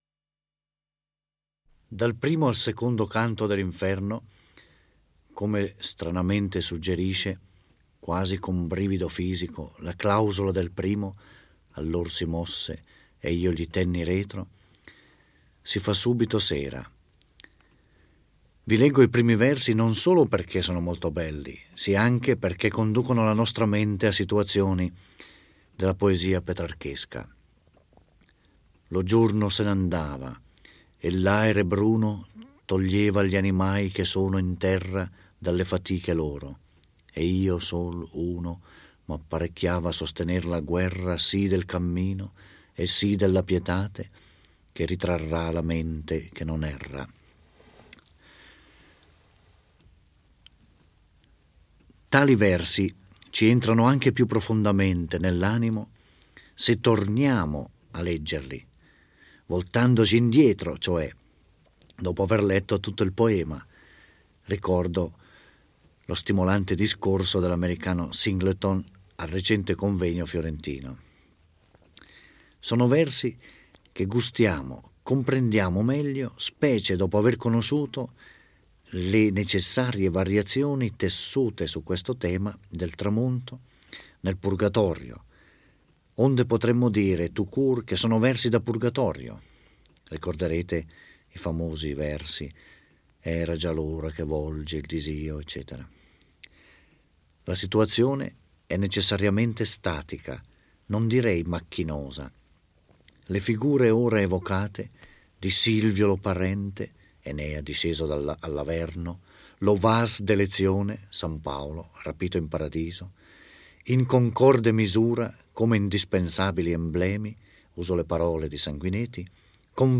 Giorgio Orelli legge e commenta il II canto dell'Inferno.